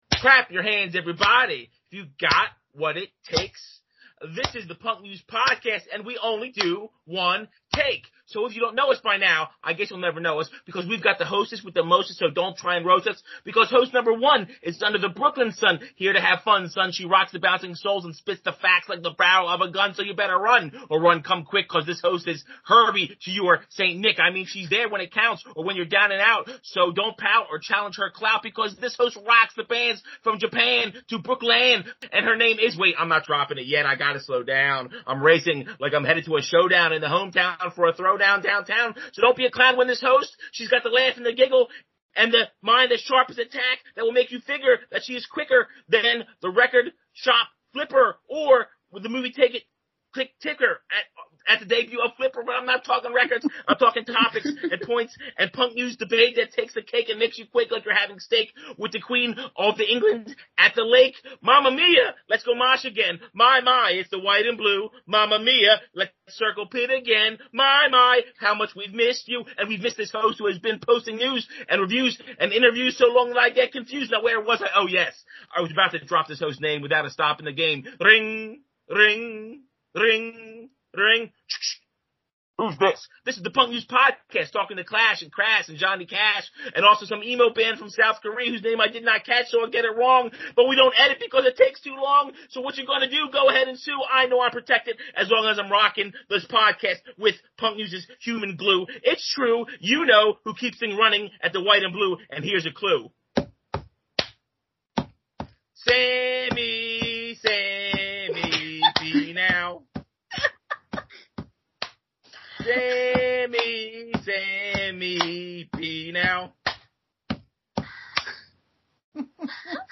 The whole gang reconvenes to talk about 2021 and they play a TON of the year's best tunes!